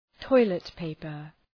Προφορά
{‘tɔılıt,peıpər}